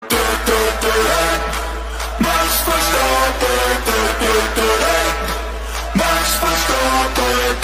Tu Tu Tu Du Max Verstappen Sound Effect Free Download